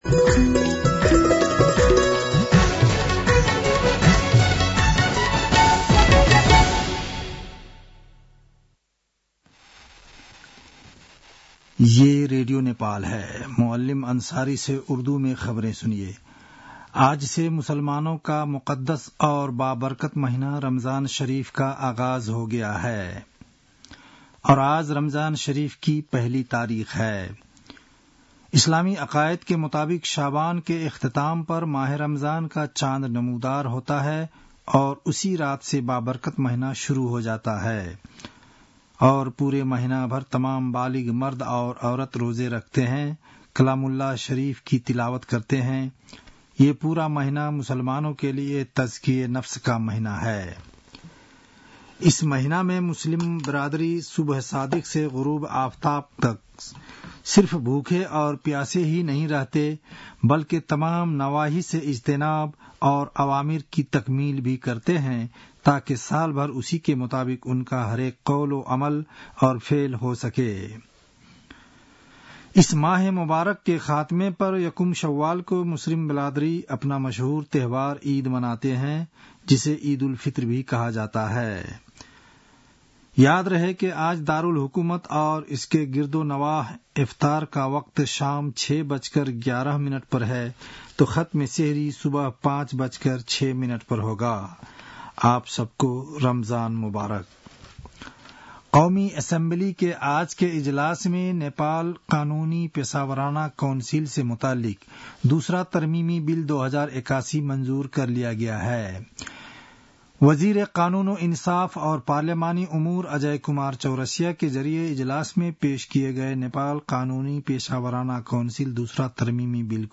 उर्दु भाषामा समाचार : १९ फागुन , २०८१